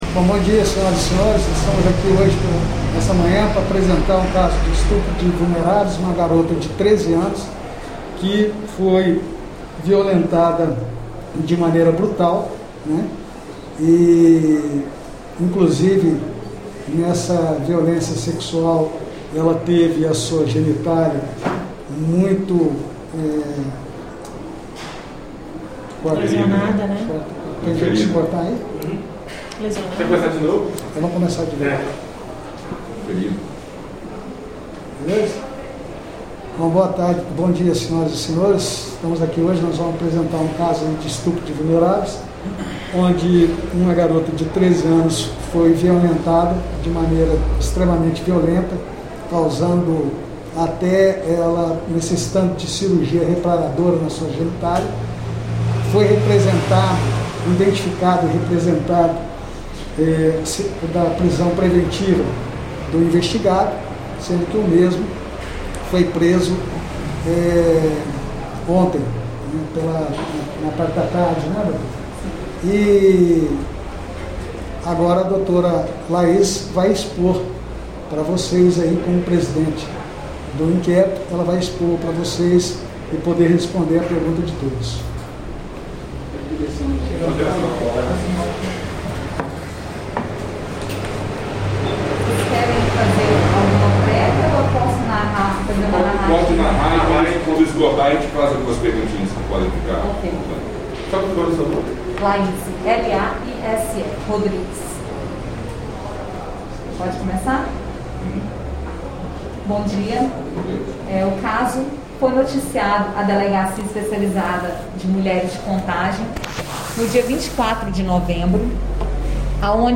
Coletiva.mp3